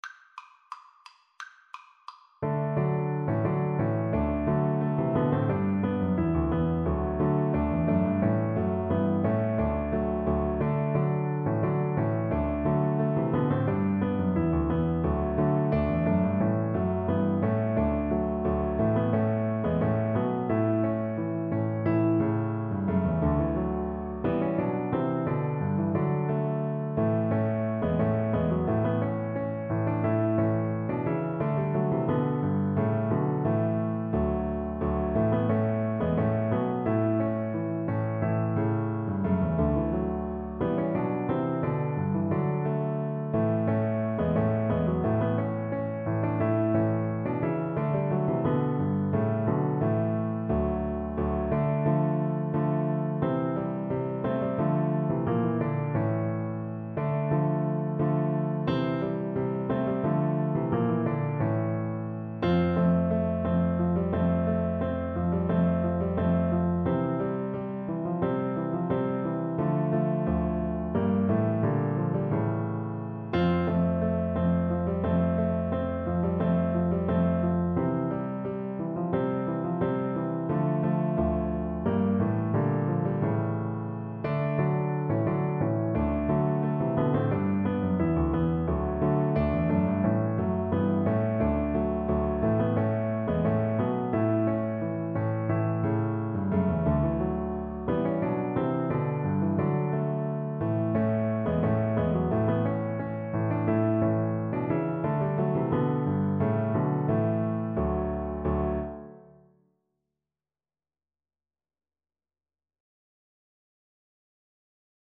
Cello
2/2 (View more 2/2 Music)
= c. 88
Arrangement for Cello and Piano
D major (Sounding Pitch) (View more D major Music for Cello )
Classical (View more Classical Cello Music)